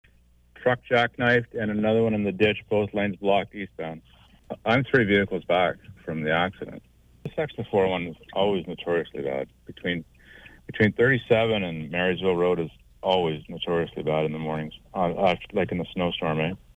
A caller to Quinte News is three vehicles behind the incident.